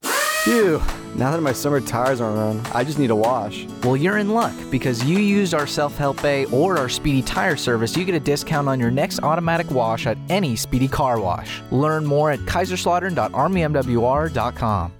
Radio Spot - Summer Tire Change